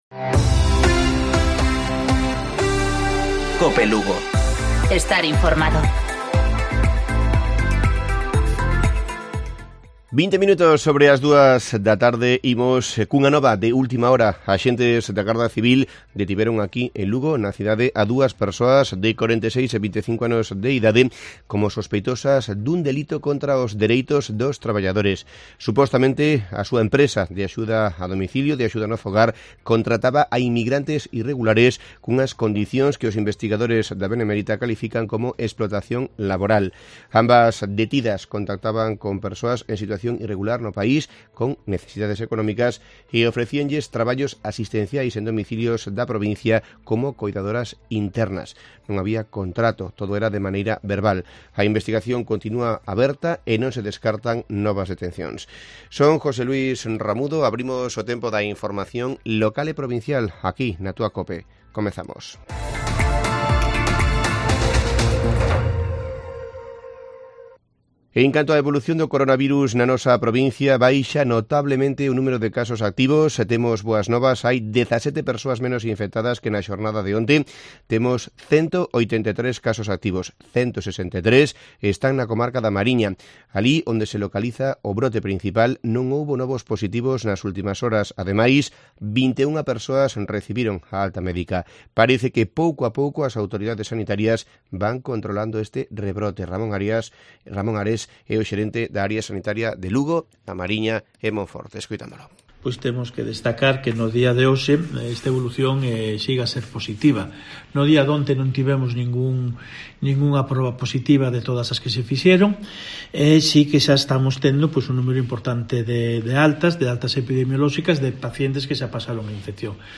Informativo Mediodía Cope. 14 de julio. 14:20-14:30 horas